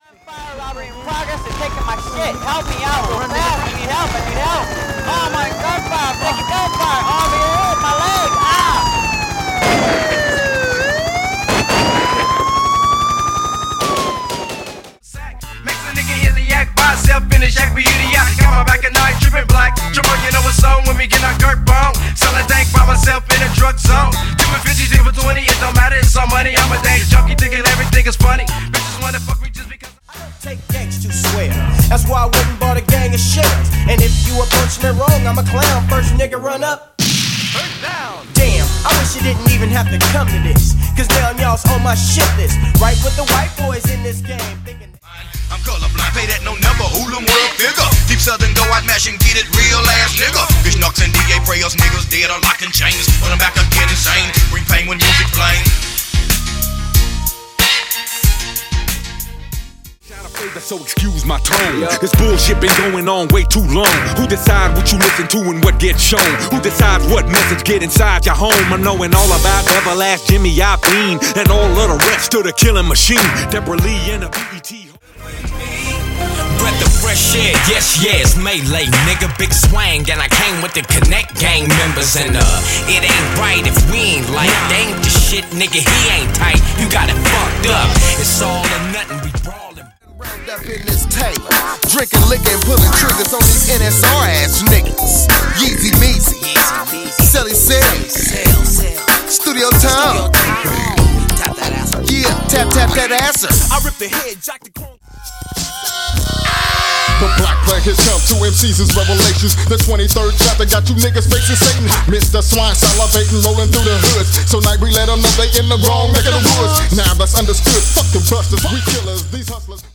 古き良きG-FUNKと、NEW WESTが絶妙なバランスで入っており、
一枚を通してウェッサイサウンドの歴史を感じられる内容に♪
中でも、ラスト2曲の極上メロウSHITは完全必聴ですよ～♪